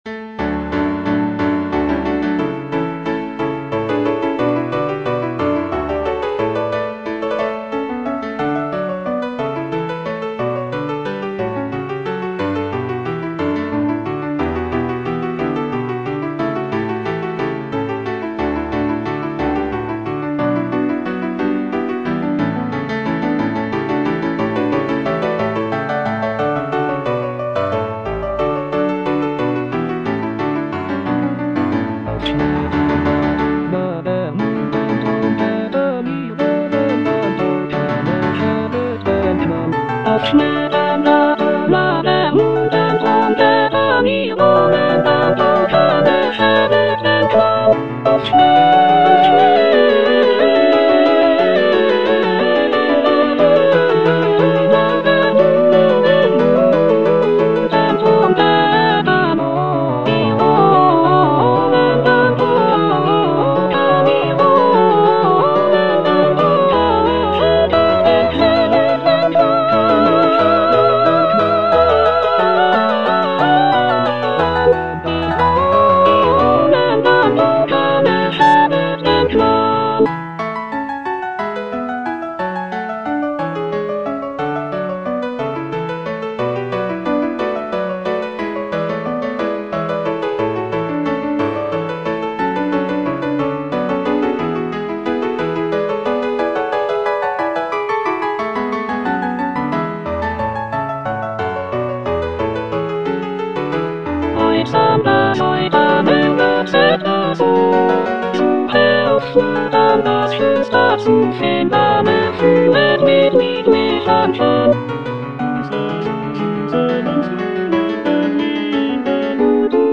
Cantata
Soprano (Emphasised voice and other voices) Ads stop